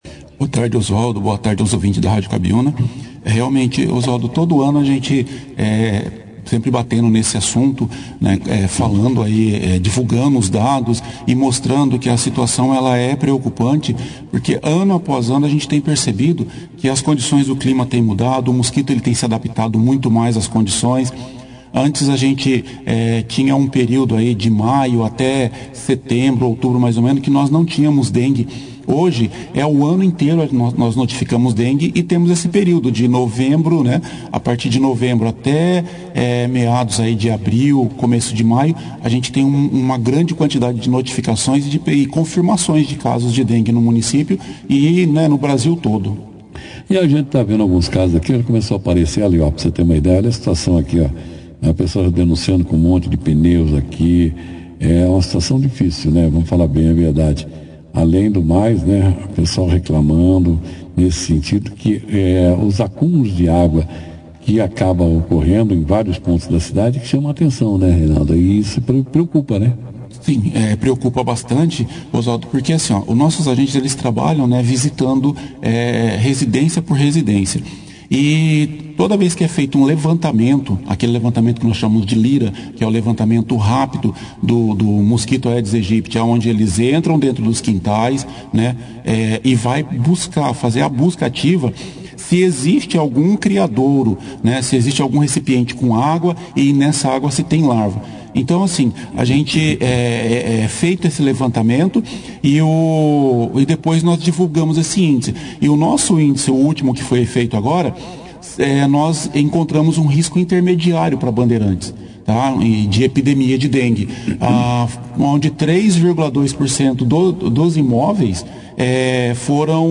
foi o destaque da 2ª edição do jornal Operação Cidade desta sexta-feira, 02/02, falando sobre a preocupante situação da incidência de dengue no município. Com 106 casos confirmados nesta semana, a cidade enfrenta um quadro epidêmico que demanda a atenção das autoridades sanitárias.